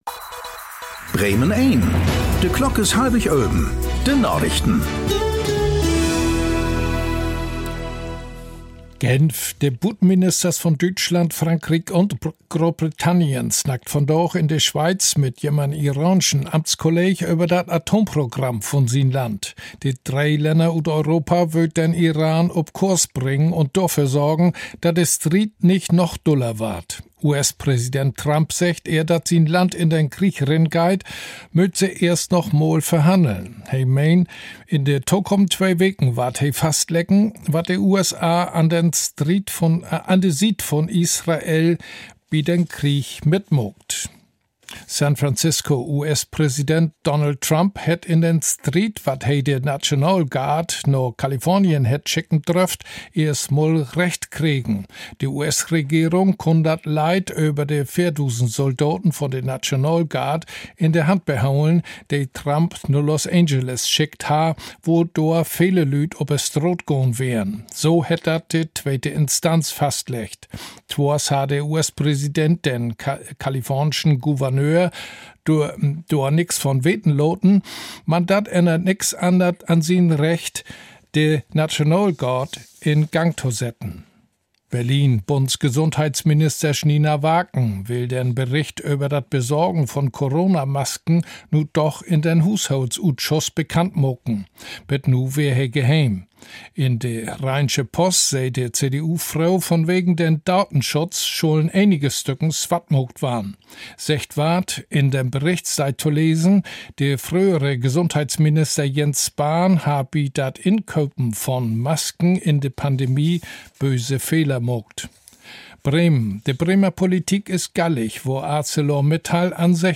Aktuelle plattdeutsche Nachrichten werktags auf Bremen Eins und hier für Sie zum Nachhören.